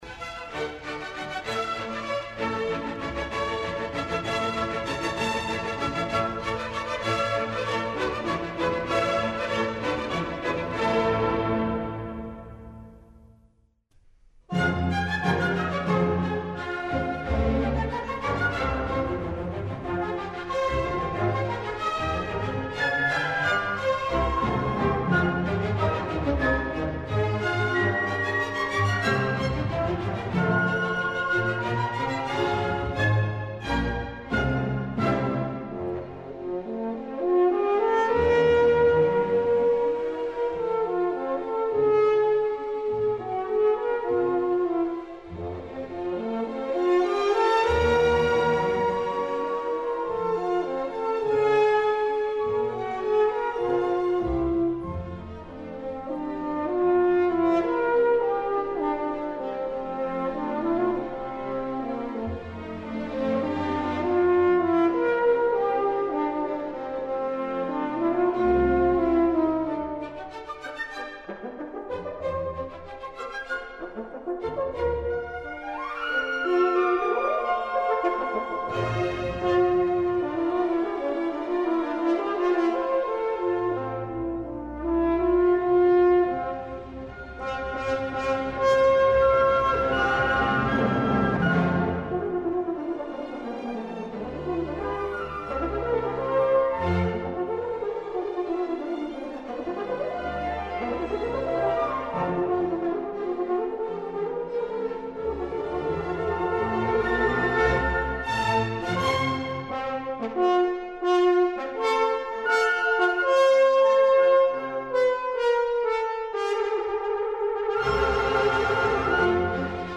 Један од технички најзахтевнијих дувачких инструмента јесте хорна, а један од најбољих извођеча на њој био је Херман Бауман, коме је посвећена данашња емисија.